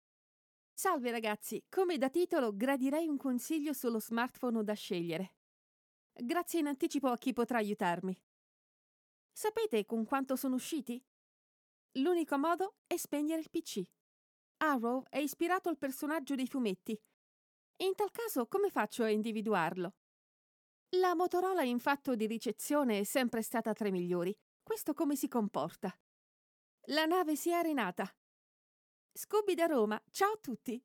Italian Voice Over
Kein Dialekt
Sprechprobe: Industrie (Muttersprache):